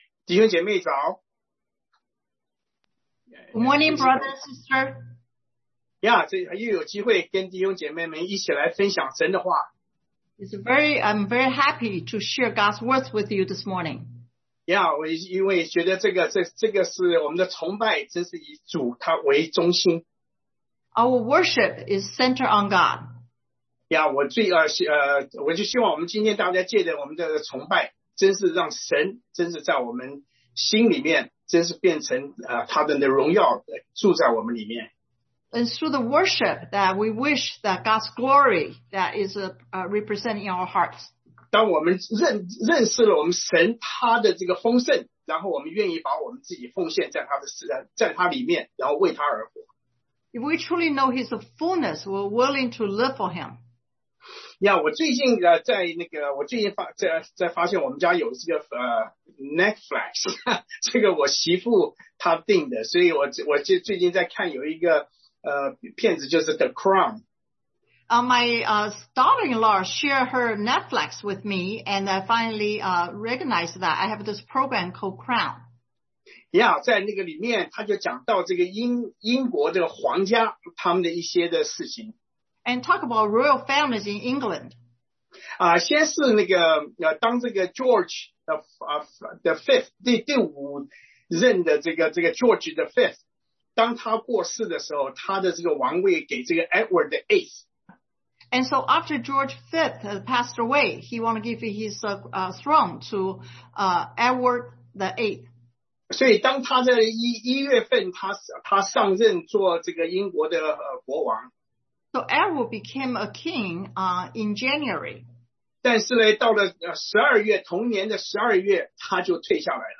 John 10:10 Service Type: Sunday AM God’s Fullness in Christ 上帝在基督裡的豐盛 1.